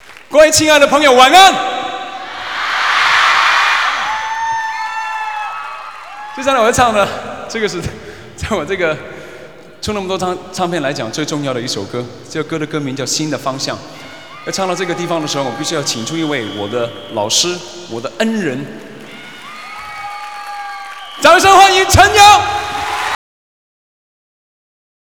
国语现场1994